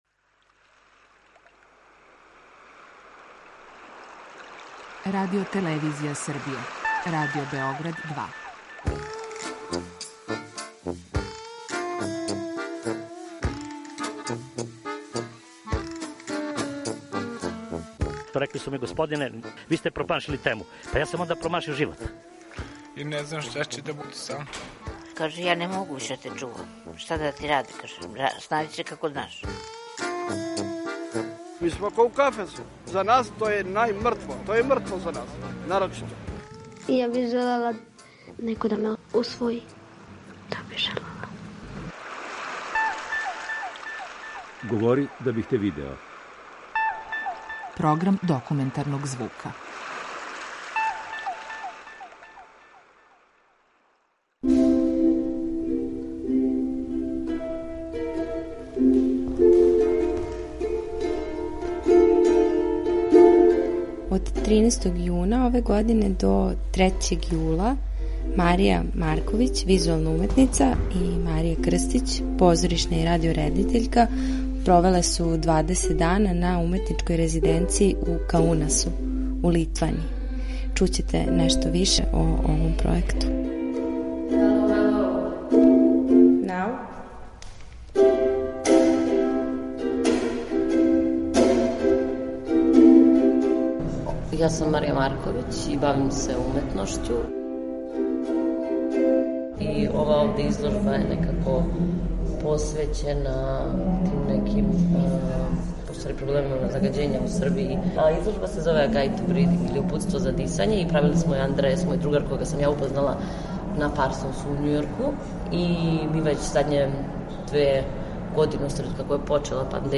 Документарни програм
У данашњој репортажи чућете разговор са обе уметнице, као и аудио-материјале из пројеката насталих током резиденцијалног боравка.